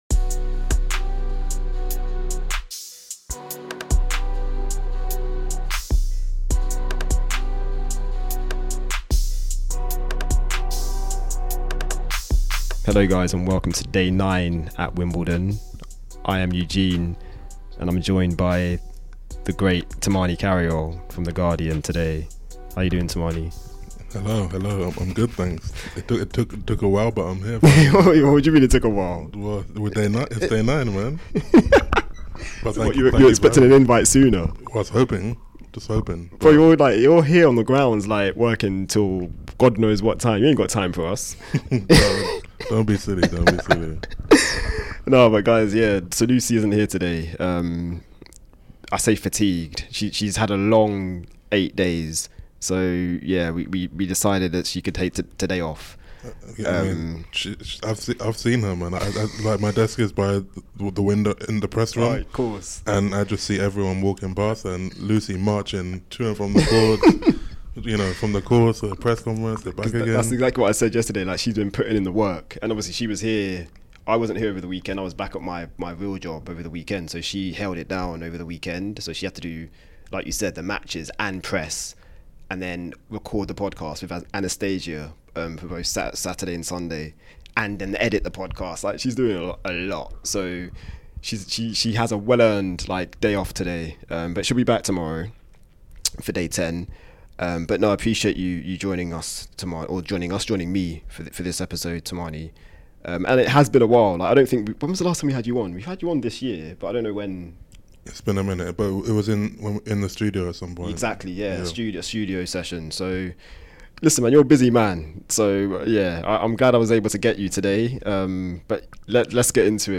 Listen out for presser snippets from a typically upbeat Paolini.